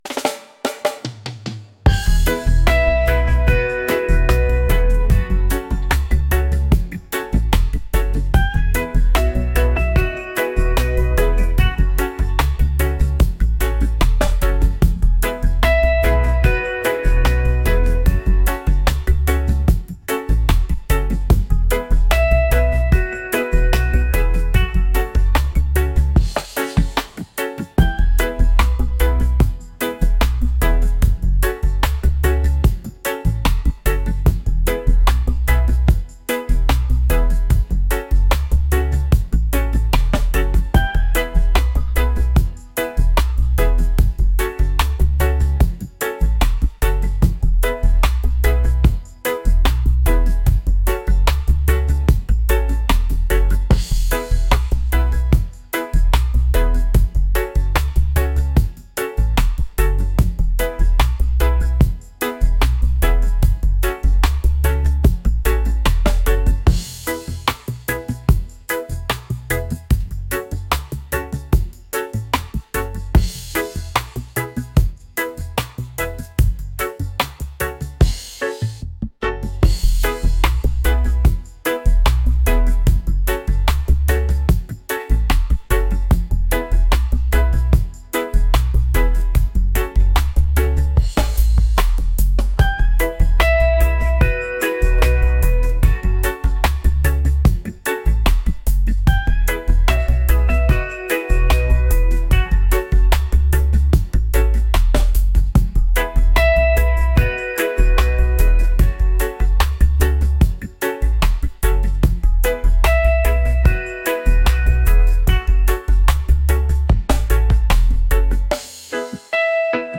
positive | vibes | reggae | laid-back